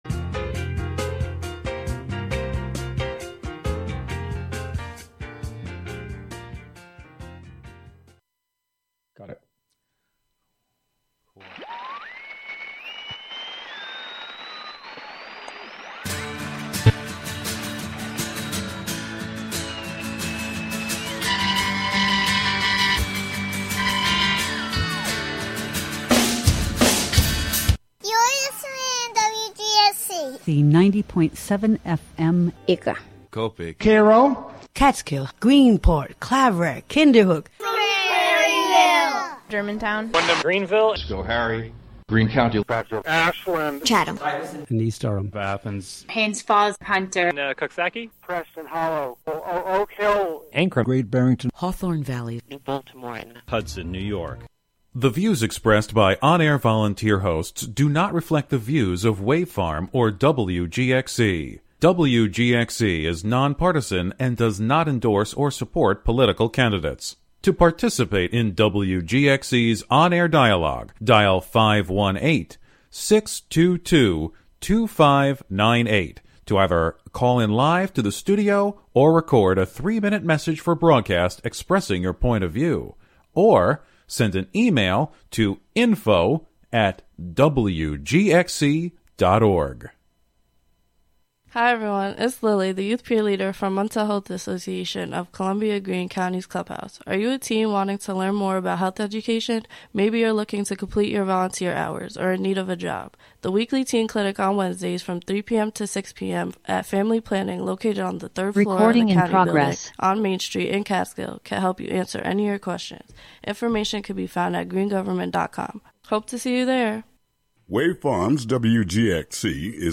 These features offer listeners real, raw, and authentic conversations.